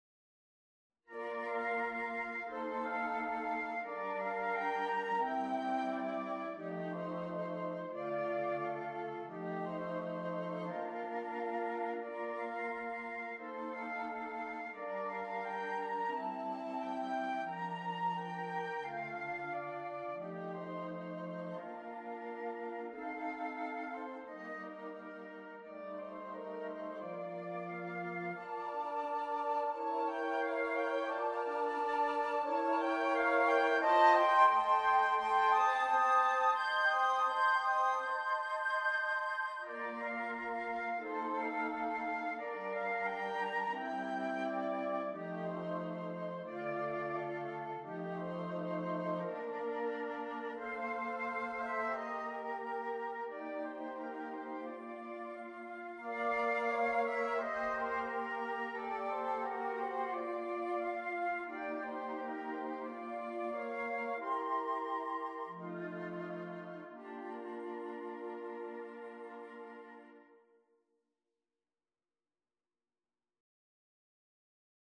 Romantic Period